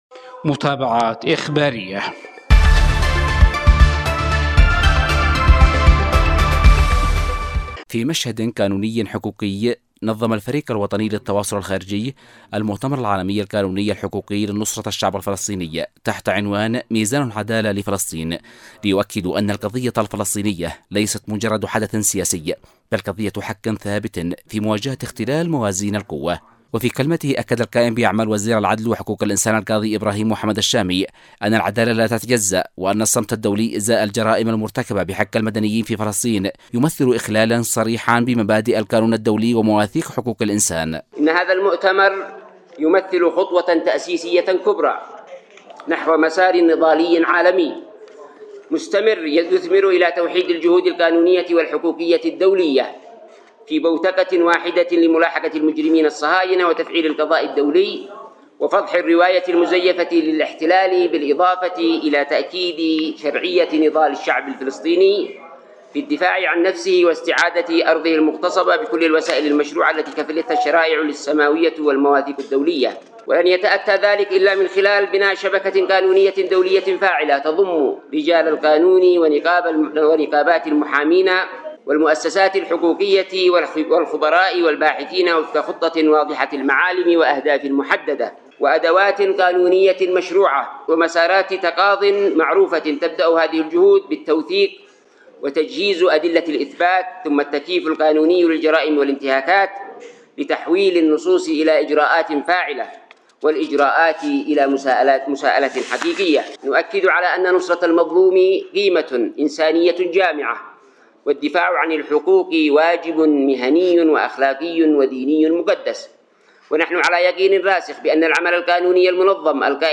متابعات-اخبارية-المؤتمر-الحقوقي-الدولي.mp3